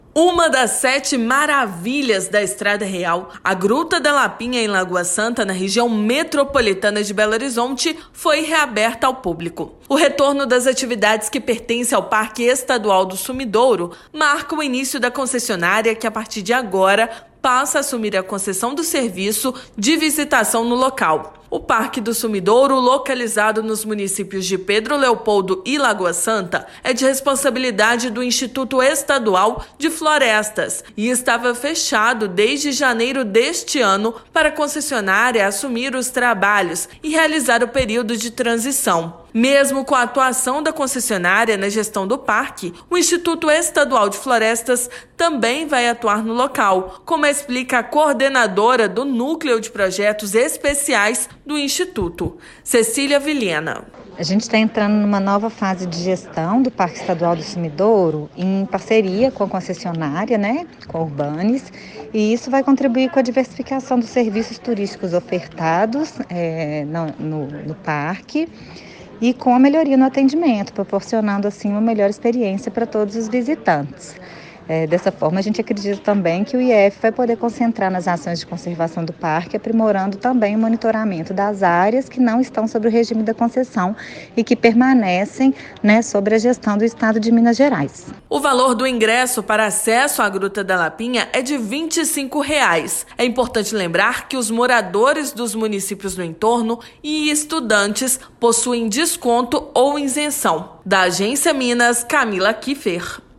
Mudanças fazem parte do Programa de Concessão de Parques Estaduais. Gestão ambiental e coordenação permanecem com o IEF. Ouça a matéria de rádio.